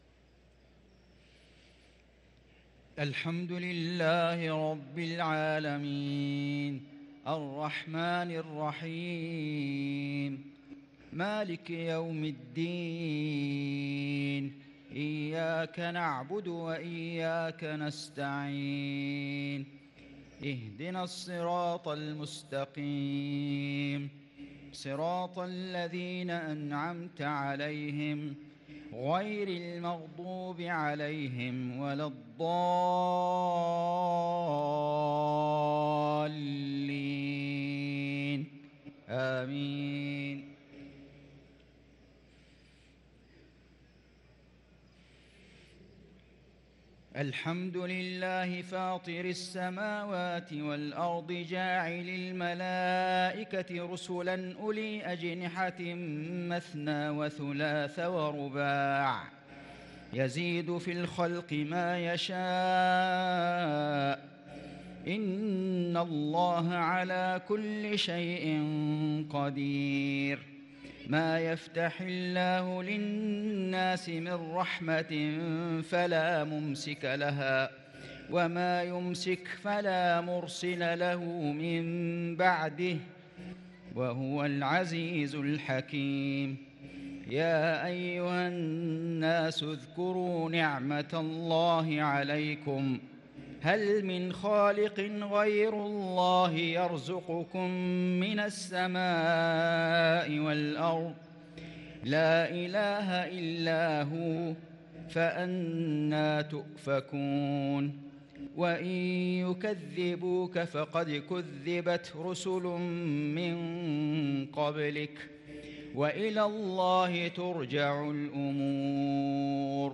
صلاة المغرب للقارئ فيصل غزاوي 19 رمضان 1443 هـ
تِلَاوَات الْحَرَمَيْن .